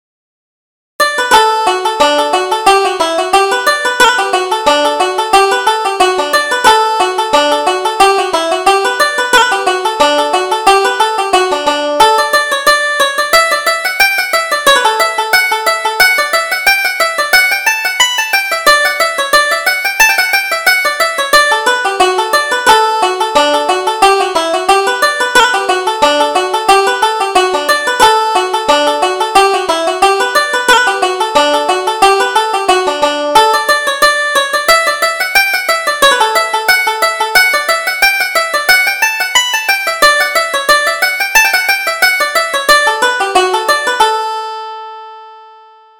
Reel: The Cameronian Reel